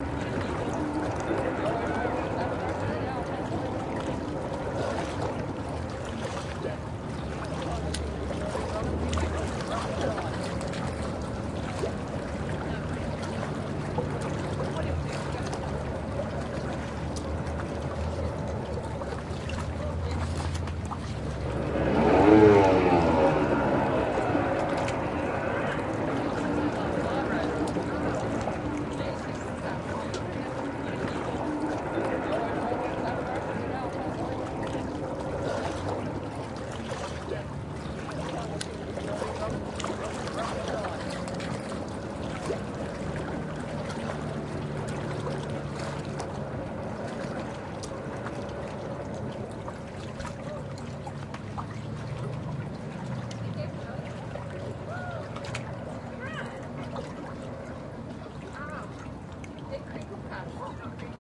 自来水
描述：2005年5月，用AT 895话筒对卡拉宾海的一艘帆船旁的水进行数字录音。
标签： 场记录 运行
声道立体声